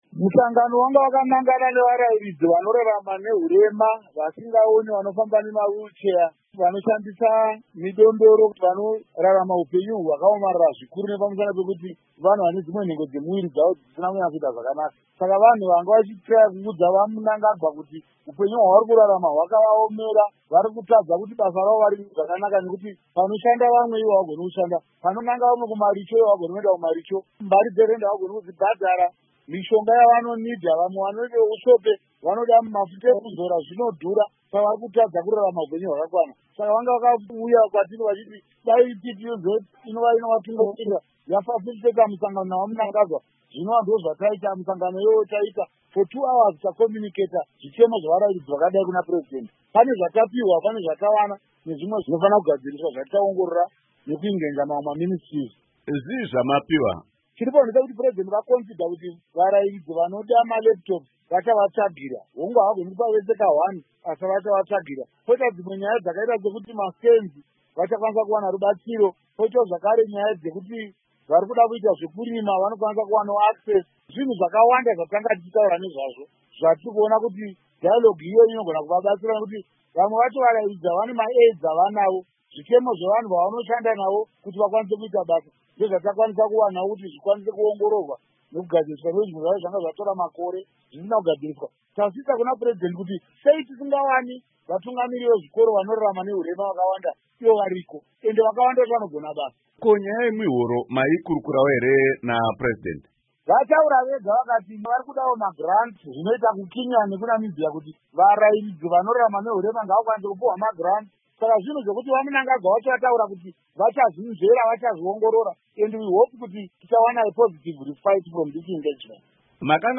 Hurukuro naVaRaymond Majongwe